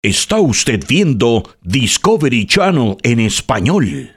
Male
Radio / TV Imaging
EspañOl: Voz Poderosa Y Vibrante Con EnergíA De Marca. DiseñAda Para Posicionar Estaciones Y Crear Identidad Sonora. English: Powerful And Vibrant Brand-Driven Voice. Designed For Station Imaging And Strong Audio Identity.